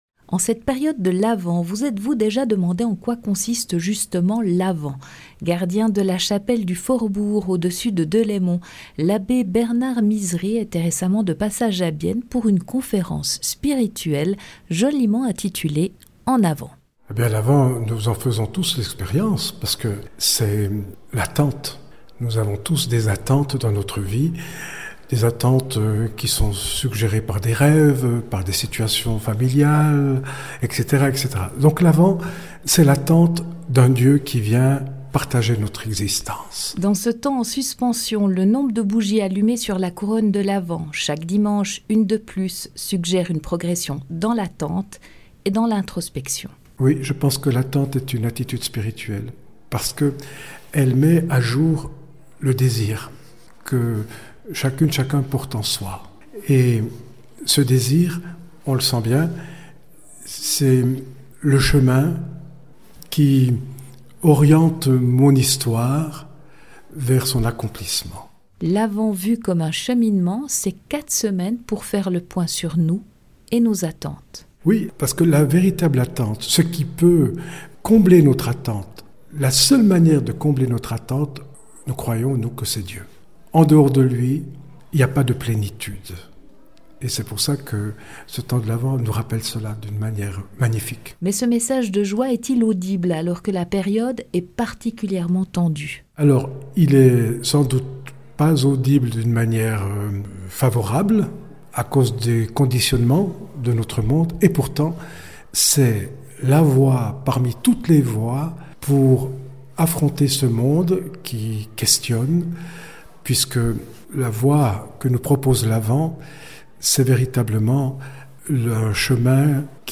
conférence spirituelle